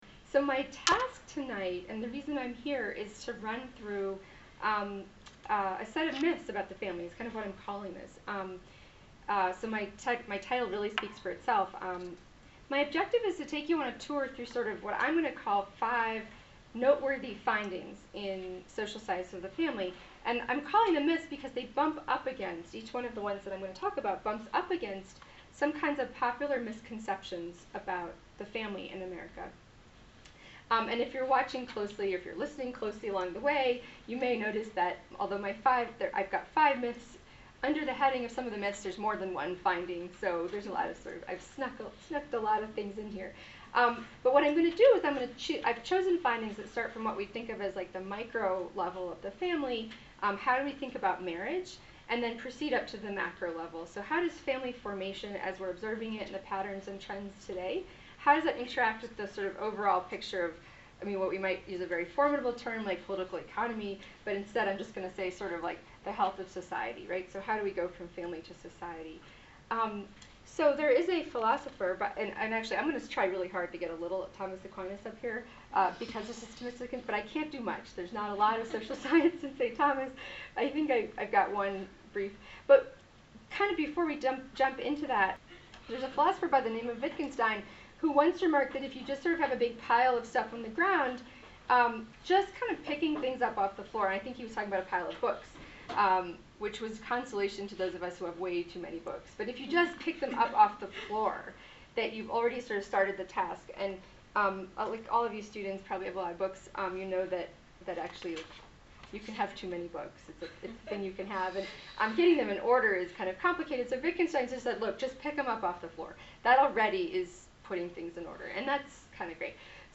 This lecture was given at the University of Utah on 14 November 2019.